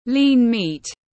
Thịt nạc tiếng anh gọi là lean meat, phiên âm tiếng anh đọc là /liːn miːt/